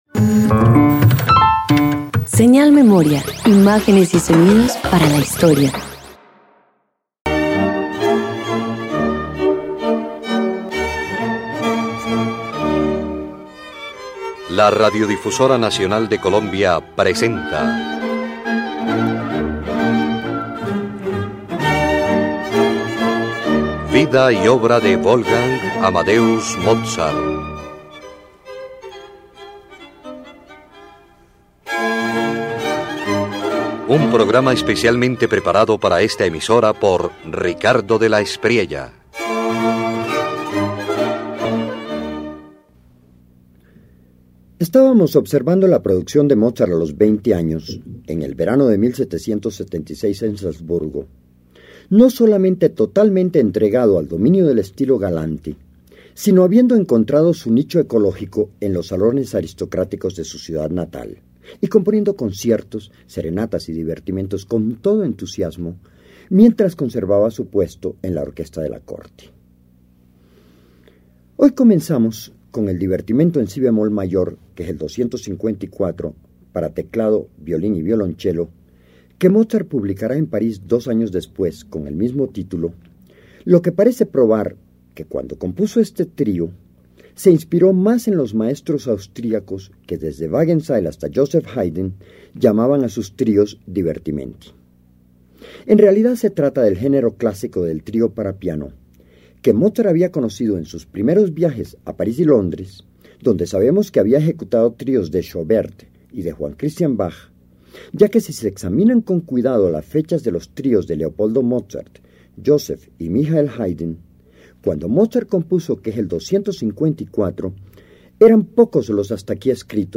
Mozart compone el Divertimento en si bemol mayor, una obra de cámara donde el teclado y el violín dialogan con elegancia mientras el violonchelo acompaña con discreción. Un ejemplo del refinado estilo galante del joven compositor.